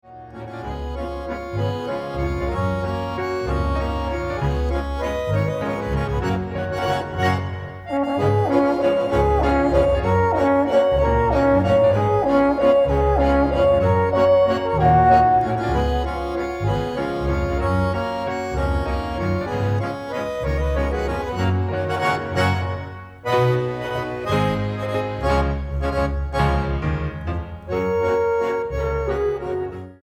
Ländler für Alphorn und Ländlerkapelle
Beschreibung:Volksmusik; Ländlerkapelle
Besetzung:Ländlerkapelle, Alphorn